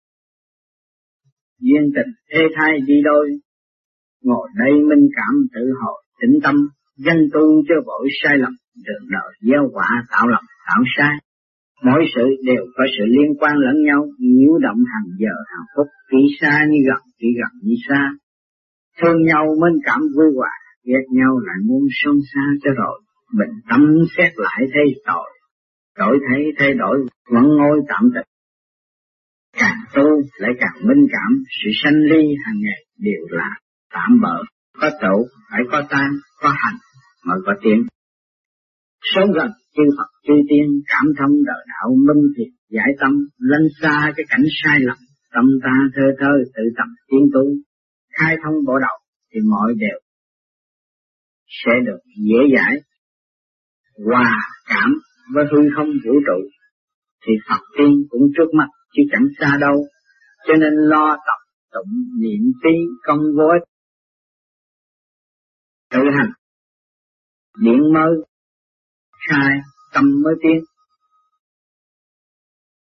Mẫu Ái Giảng Tại Việt Nam Mẫu Ái - 1973